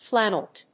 音節flan・nel・ette 発音記号・読み方
/fl`ænəlét(米国英語)/